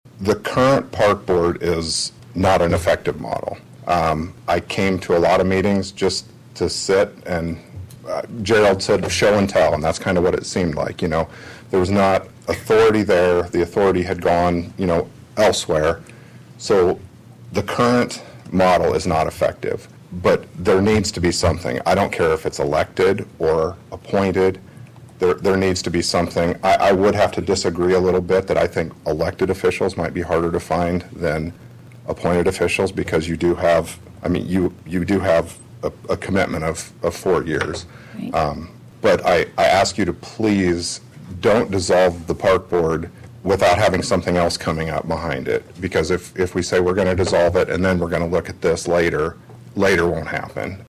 (Atlantic) A public hearing was held at the Atlantic City Council meeting Wednesday evening on a proposal to discontinue the Atlantic Parks and Recreation Board.